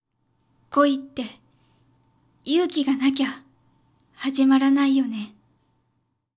ボイス
キュート女性
josei_koitteyuukiganakyahazimaranaiyone.mp3